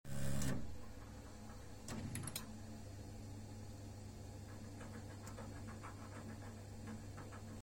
L'autre à l’arrêt brutal des vibrations au moment ou on commence à tirer de l'eau chaude.
Arrêt bruit chaudière mp3
arret-bruit-chaudiere.mp3